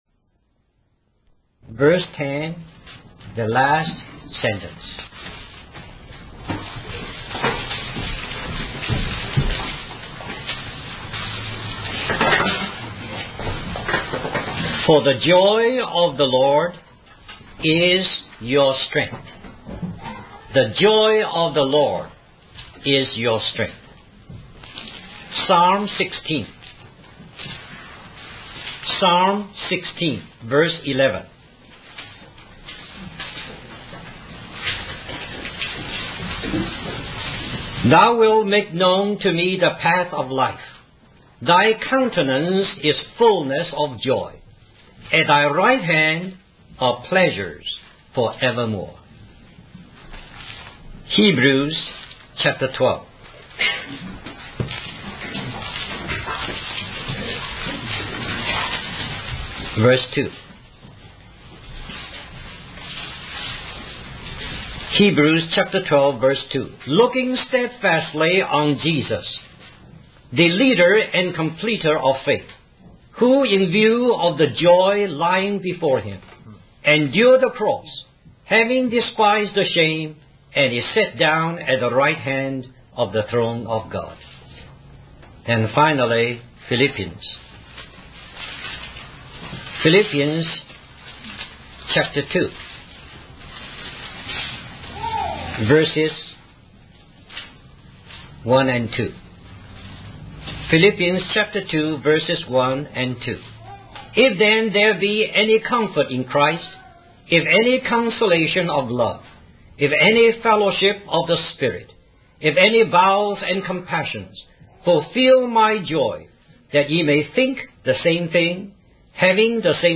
In this sermon, the preacher emphasizes that true joy can only be found in the Lord.